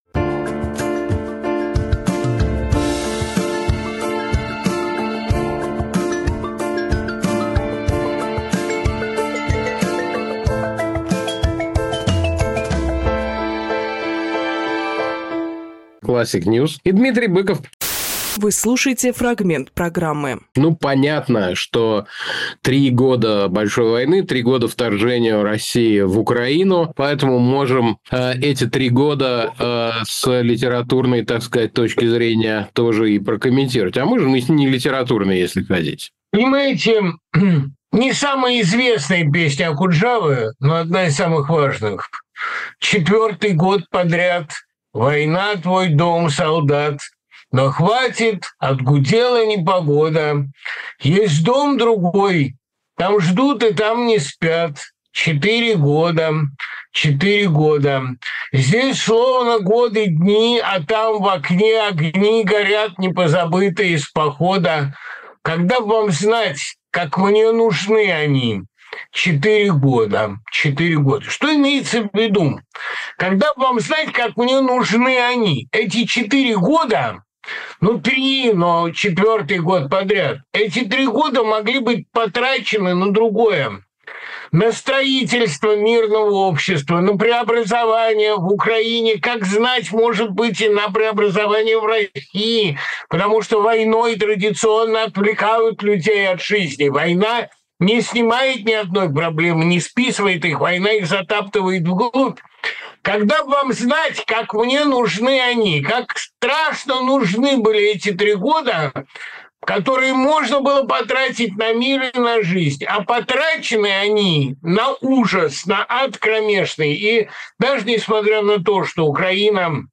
Дмитрий Быковпоэт, писатель, журналист
Александр Плющевжурналист
Фрагмент эфира от 23.02